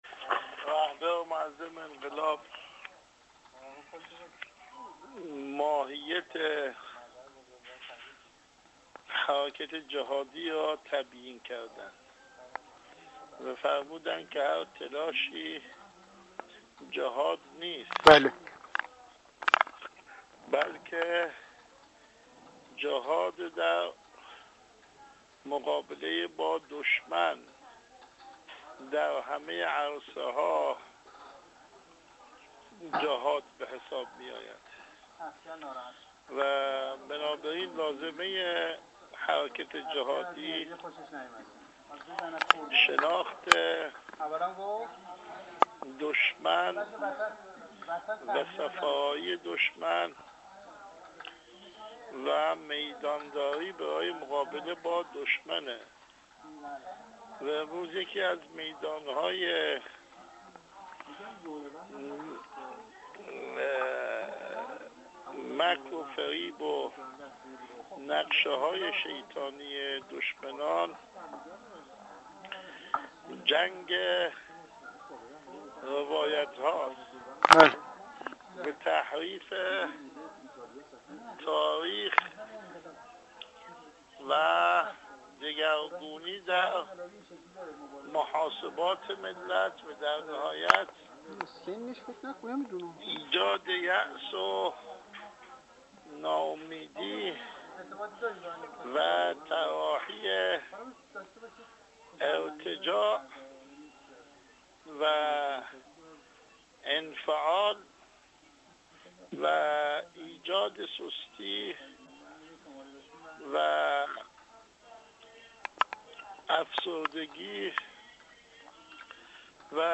آیت‌الله عباس کعبی، عضو هیئت رئیسه مجلس خبرگان رهبری، در گفت‌وگو با ایکنا درباره تأکید چندین باره رهبر معظم انقلاب در سخنرانی‌های اخیر بر مسئله «جهاد تبیین» و مقابله با روایت‌سازی‌های جعلی دشمنان گفت: رهبر معظم انقلاب در سخنان اخیر ماهیت حرکت جهادی را تبیین کردند و هر حرکت و تلاشی را جهاد ندانستند، بلکه تلاش در مقابله با دشمن در همه عرصه‌ها را جهاد تعریف کردند، لذا لازمه حرکت جهادی شناخت دشمن و نوع صف‌آرایی دشمن و میدان‌داری برای مقابله با دشمن است.